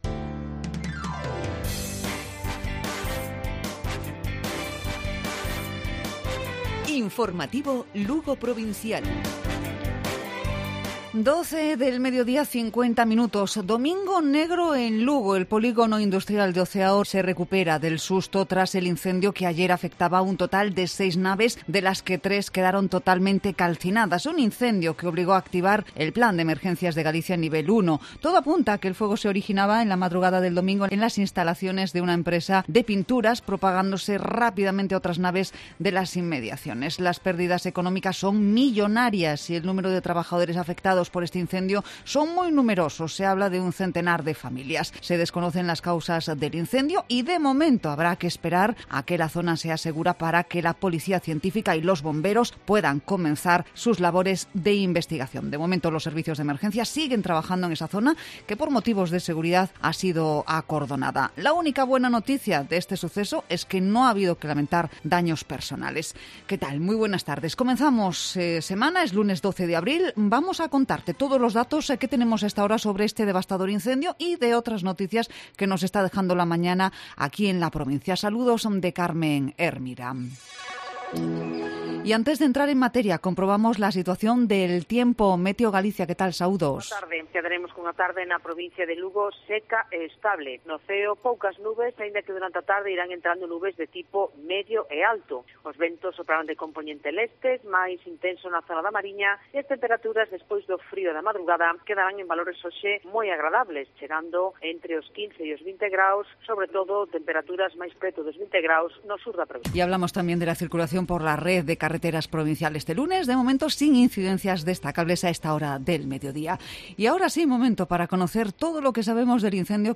Informativo Provincial Cope Lugo.